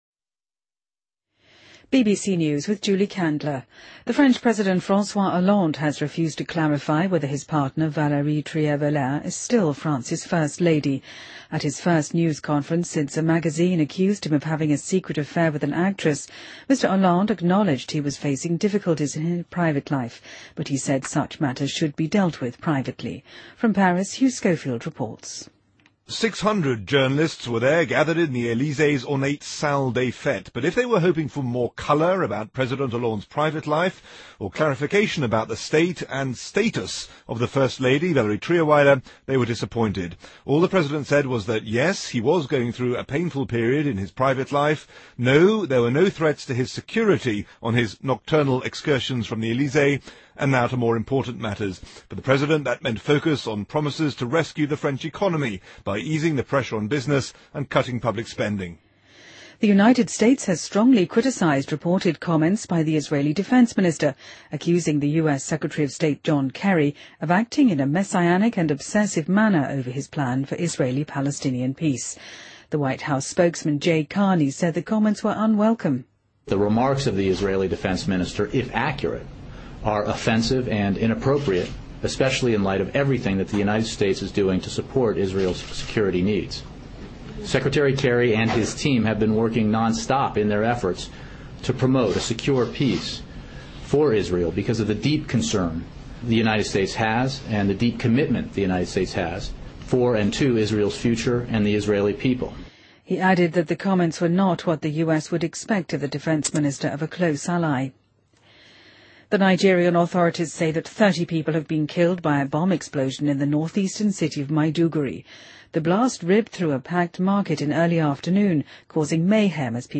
BBC news,2014-01-15